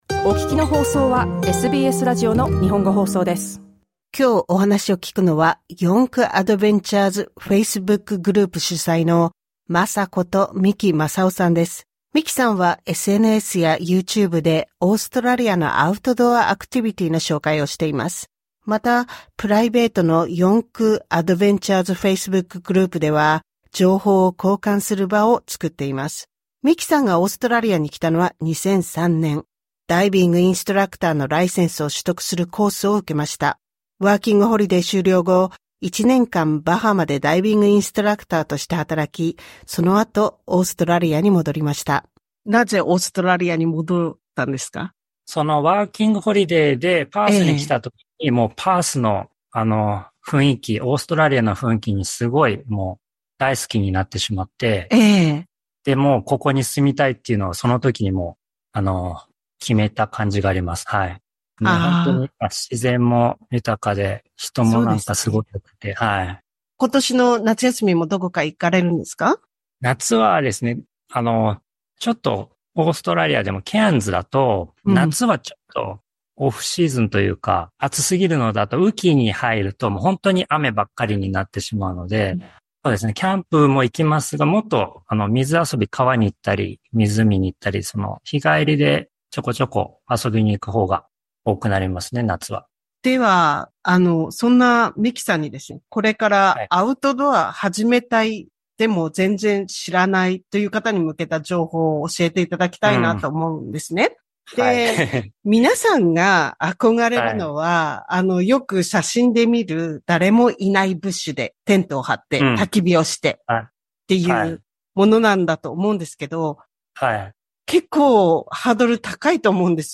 詳しくはインタビューでどうぞ。